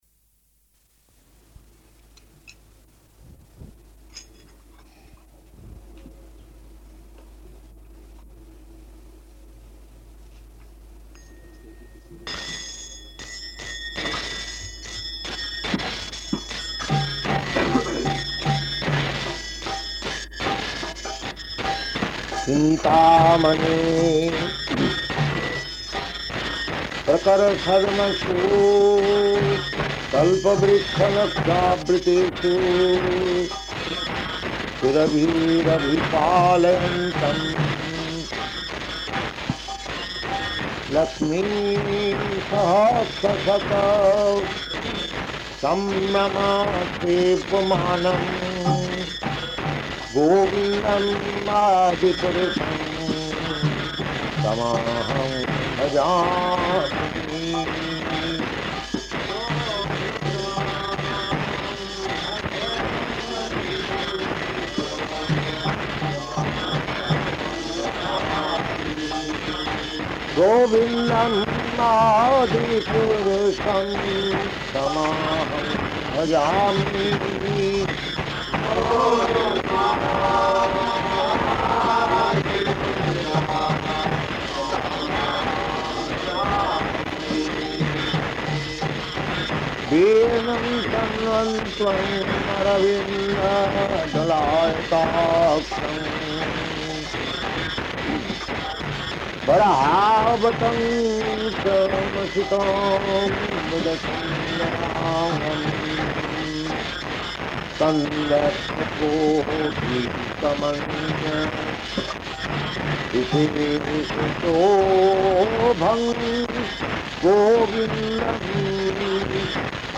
Location: London
Prabhupāda: [leads singing of Brahma-saṁhitā 5.29–33]